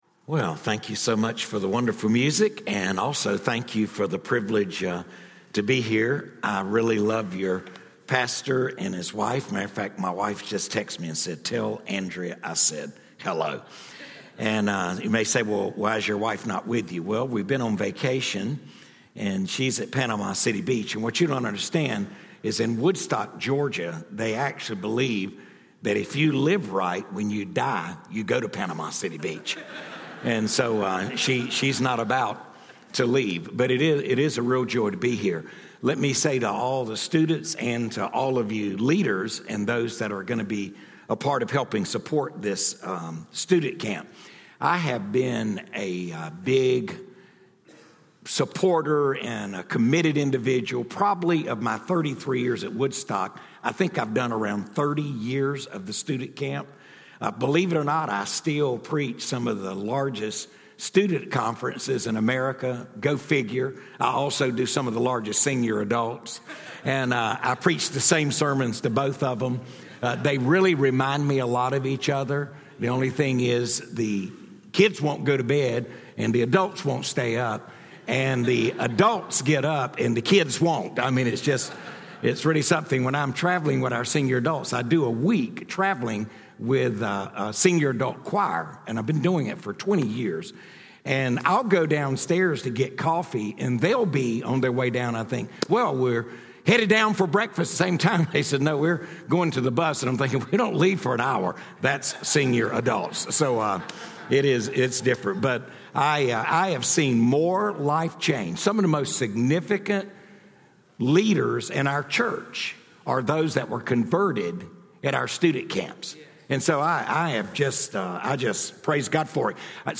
From the evening worship service on Sunday, June 23, 2019